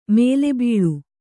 ♪ mēle bīḷu